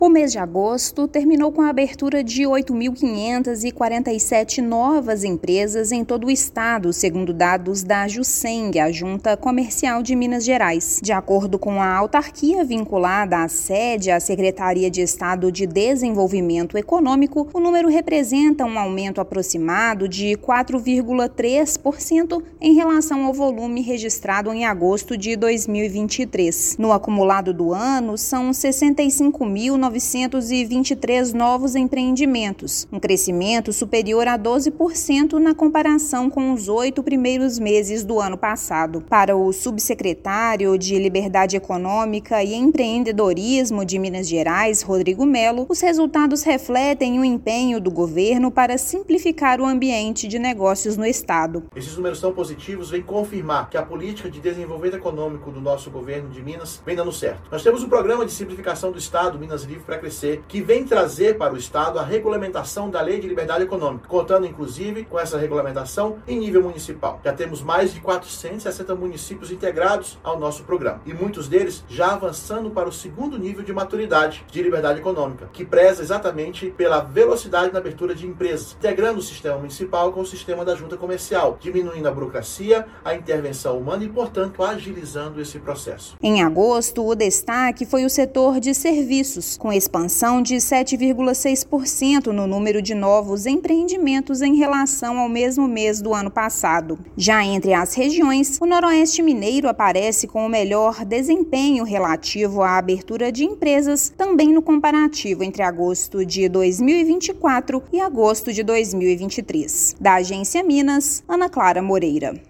[RÁDIO] Minas recebeu mais de 8,5 mil novas empresas em agosto
No acumulado do ano, já são quase 66 mil novos empreendimentos em todas as regiões do estado, que mantém crescimento de 12% frente ao mesmo período de 2023. Ouça matéria de rádio.